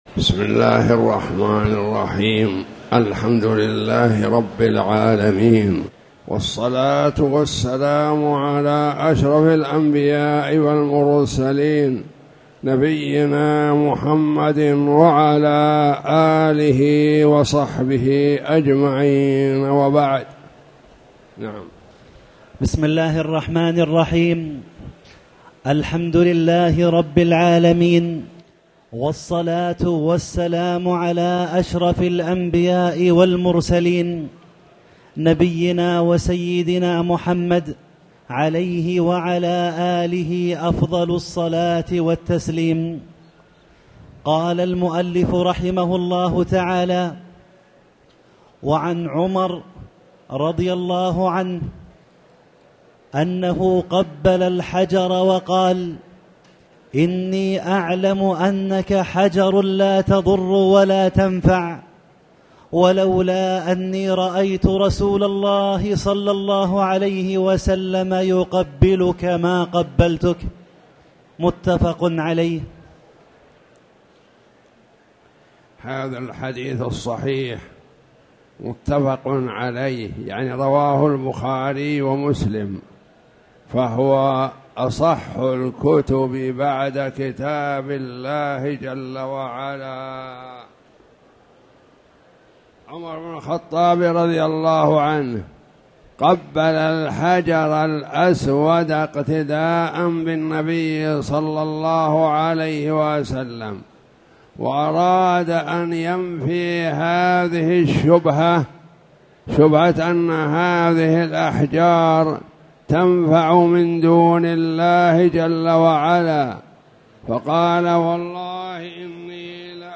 تاريخ النشر ١٩ ذو الحجة ١٤٣٨ هـ المكان: المسجد الحرام الشيخ